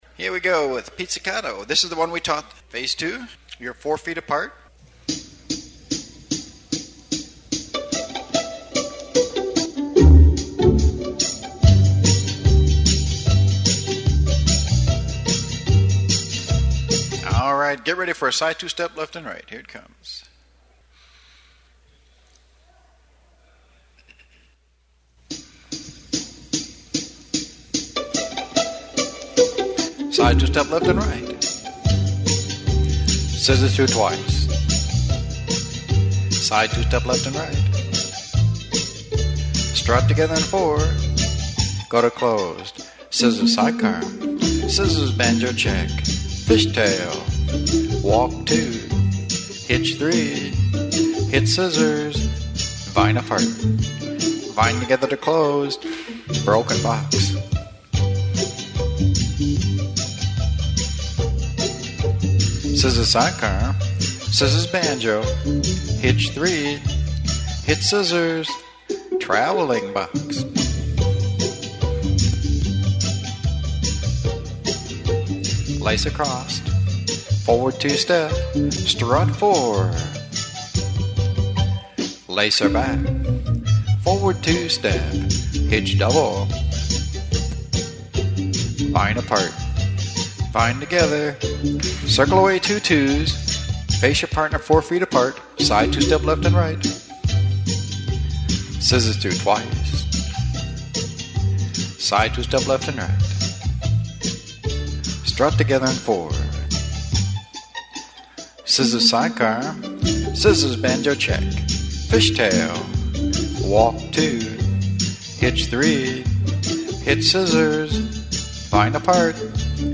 Cued Music
Two Step II